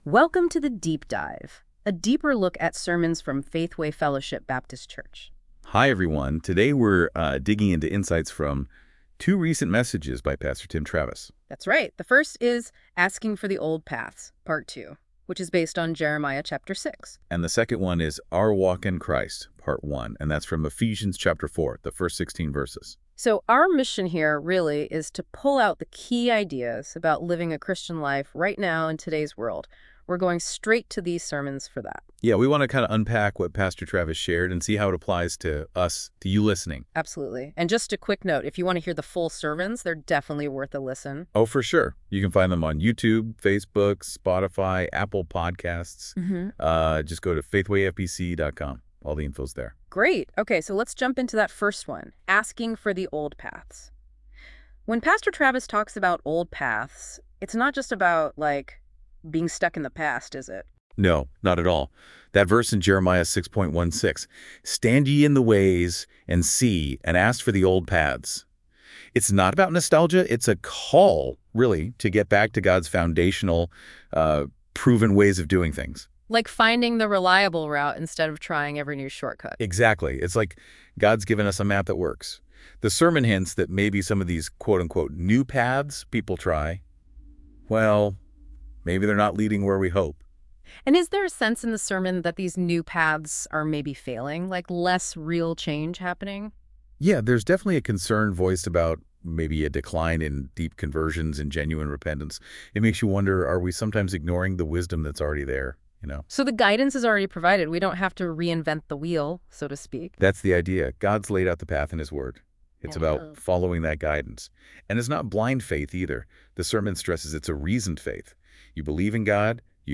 This content is AI generated for fun.